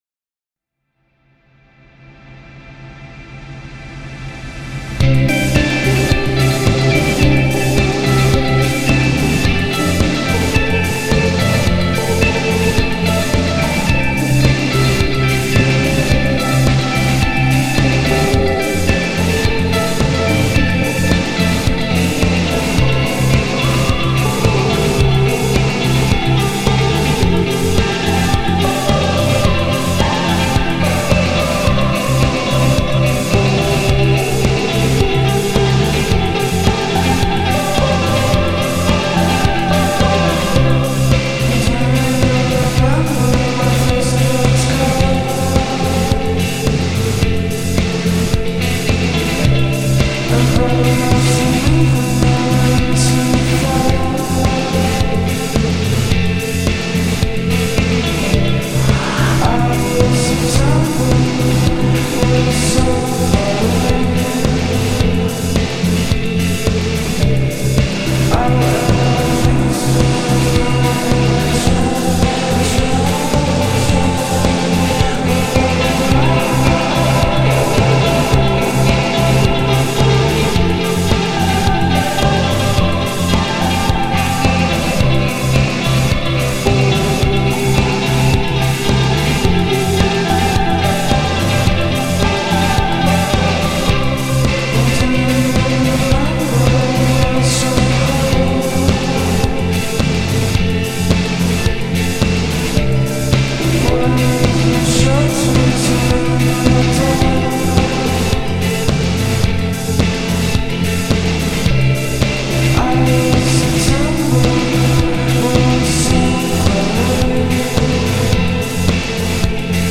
dreampop
a beautifully unfolding effort